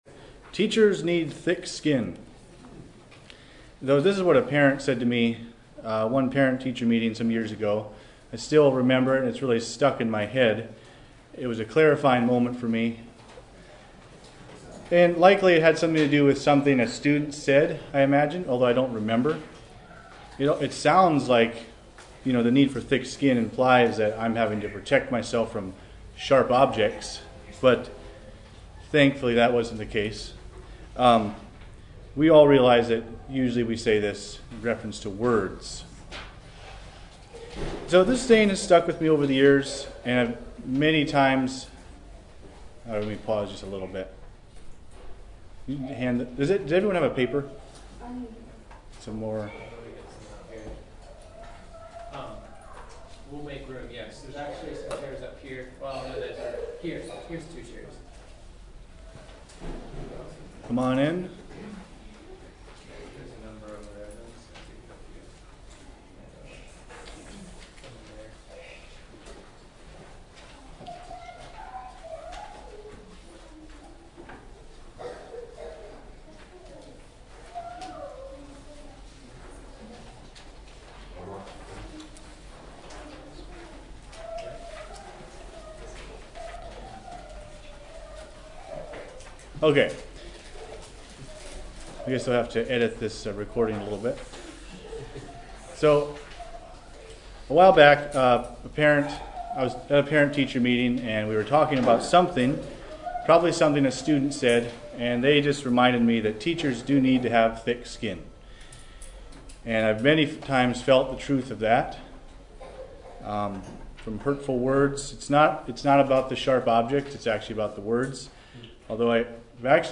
Home » Lectures » Coping with Criticism